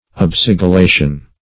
Search Result for " obsigillation" : The Collaborative International Dictionary of English v.0.48: Obsigillation \Ob*sig`il*la"tion\, n. [L. ob (see Ob- ) + sigillum a seal.]